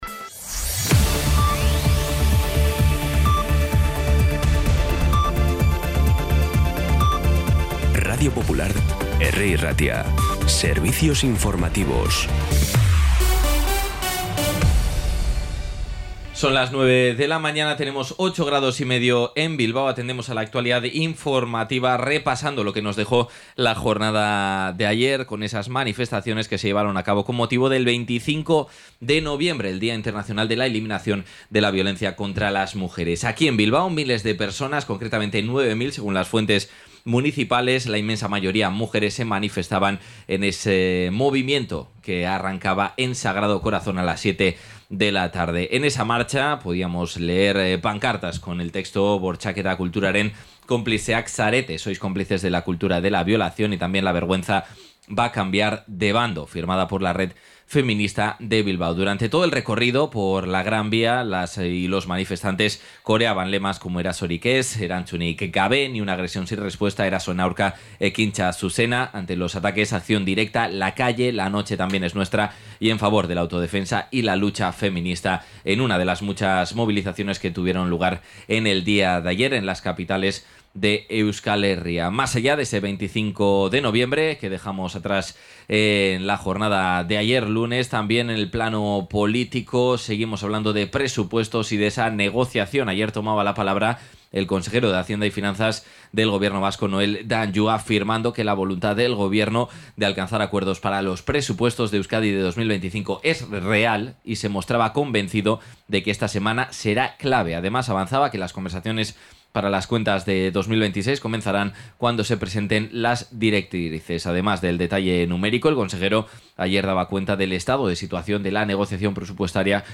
Las noticias de Bilbao y Bizkaia del 26 de noviembre a las 9